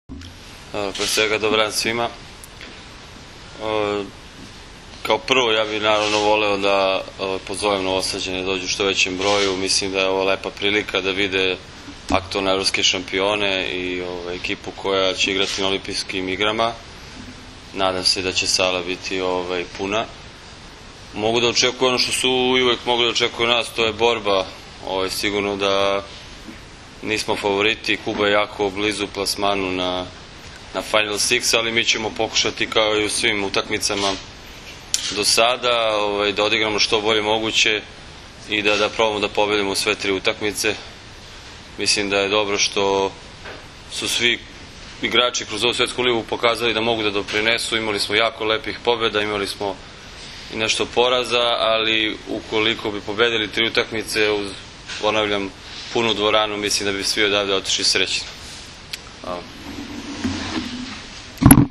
Danas je u Medija centru SPC „Vojvodina“ u Novom Sadu održana konferencija za novinare, kojoj su prisustvovali kapiteni i treneri Kube, Srbije, Rusije i Japana
IZJAVA